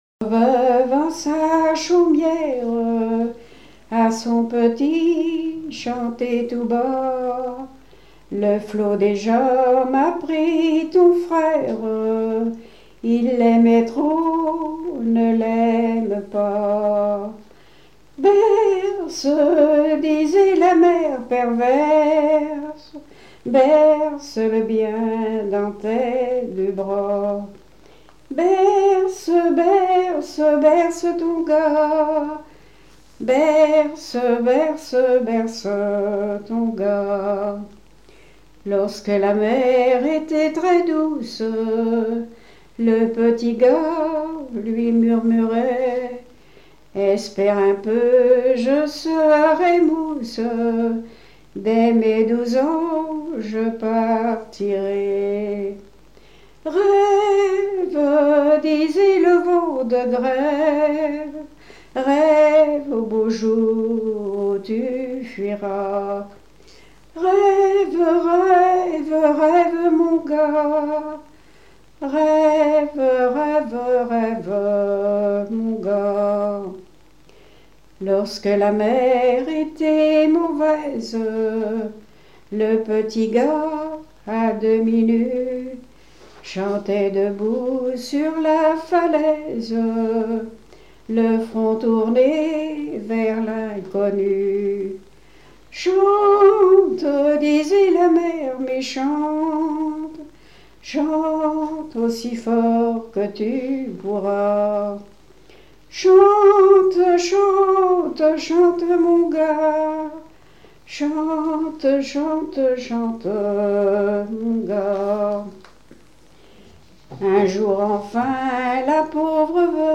Genre strophique
Chansons de variété
Pièce musicale inédite